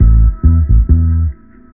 BIG125BASS-L.wav